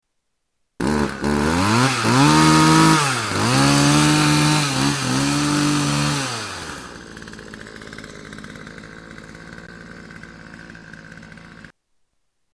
Chainsaw sound 10